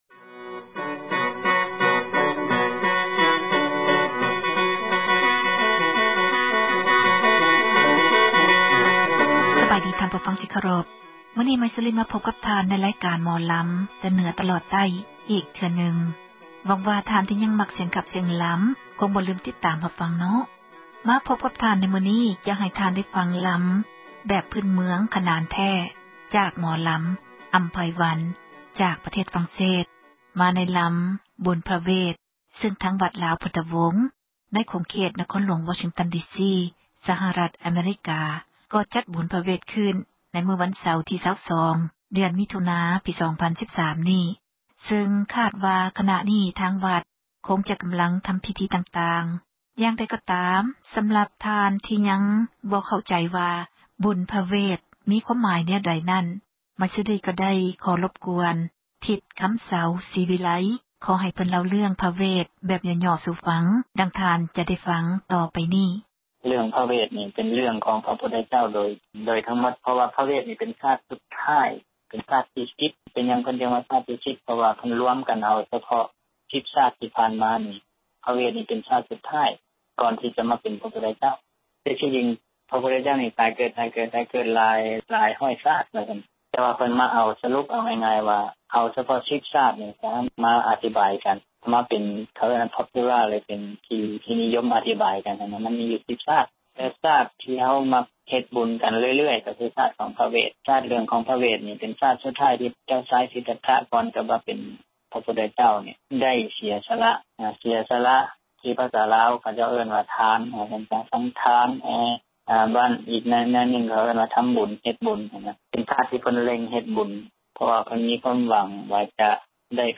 ໝໍລຳ ລາວ: ສິລປະ ການຂັບລໍາ ຂອງ ເຊື້ອສາຍ ລາວ ໃນແຕ່ລະ ຊົນເຜົ່າ ແຕ່ລະ ພື້ນເມືອງ.
ເນື່ອງໃນວັນ ບຸນພະເຫວດ ສັນດອນ ພ.ສ 2555